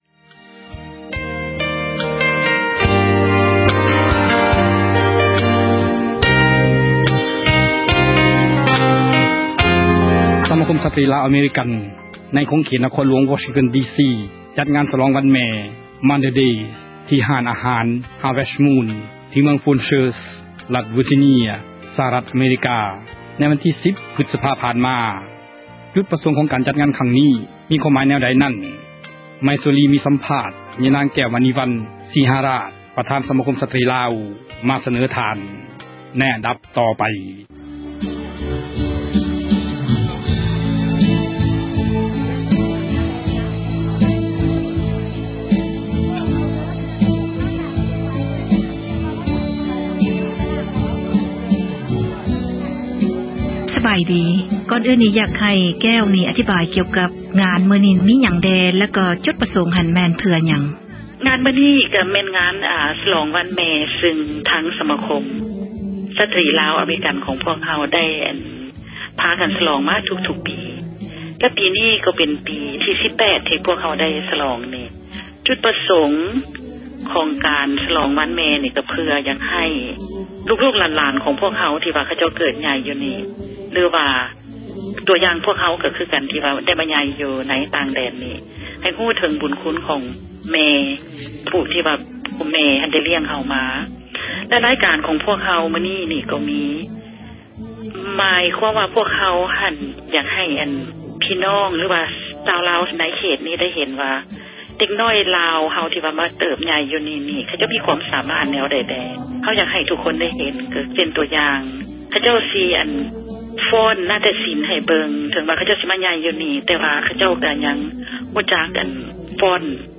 F-mother ງານສລອງ ວັນແມ່ ປະຈໍາປີ ຂອງ ສະມາຄົມ ຍິງລາວ ອະເມຣິກັນ ທີ່ ນະຄອນຫລວງ ວໍຊິງຕັນ ດີ ຊີ ສະຫະຣັດ ອະເມຣິກາ